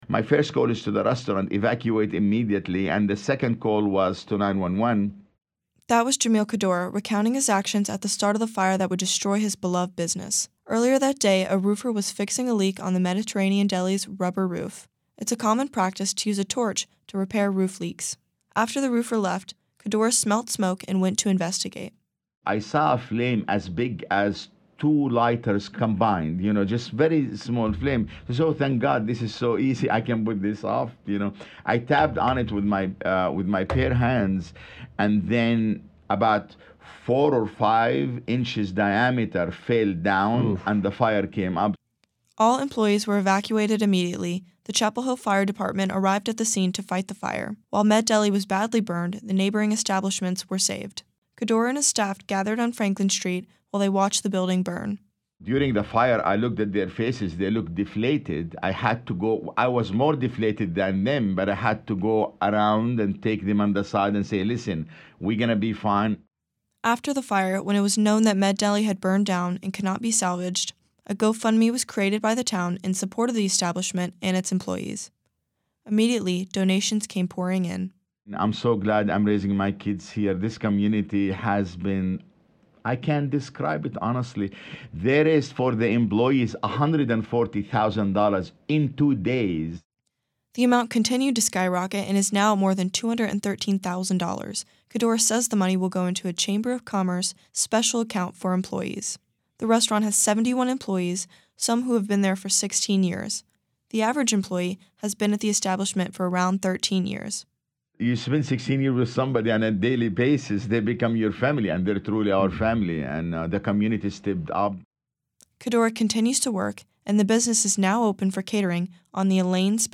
recently spoke with 97.9 The Hill about the fire, the resulting community support, and the future of the establishment.